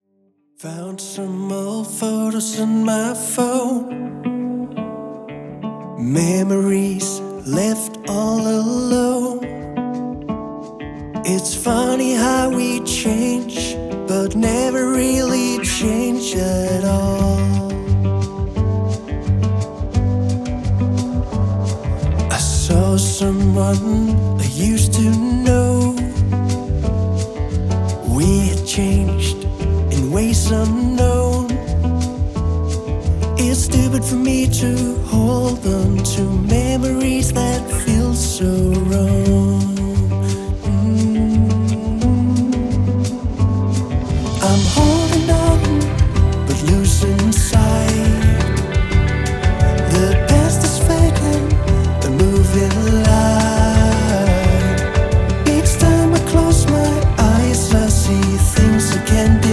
• Folk
• Singer/songwriter